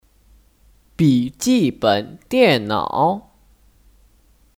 笔记本电脑 (Bǐjìběn diànnǎo 笔记本电脑)